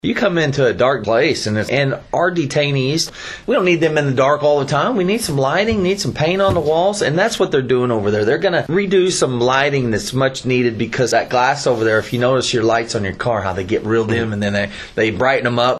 St. Francois County Commissioners approve a request for bids to upgrade outdated lighting at the county jail, citing safety concerns. Commissioner David Kater explains the need for the improvements.